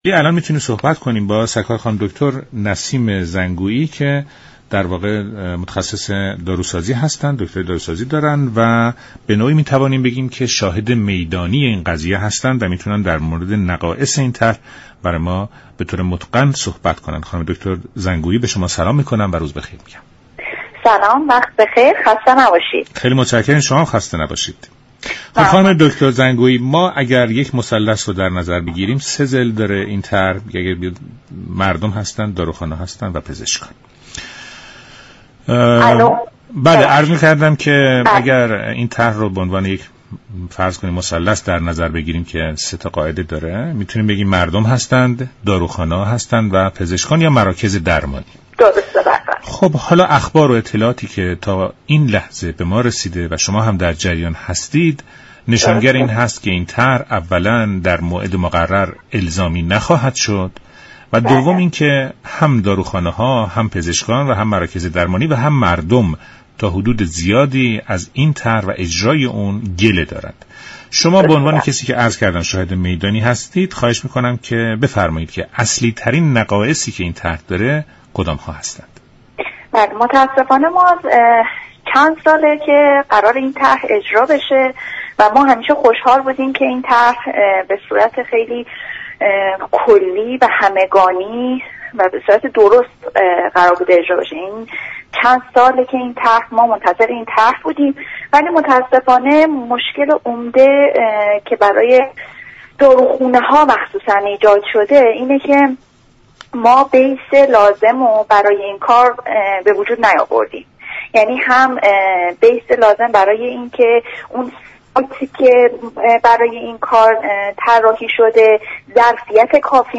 برنامه ایران امروز شنبه تا سه شنبه هر هفته ساعت 12:40 از رادیو ایران پخش می شود.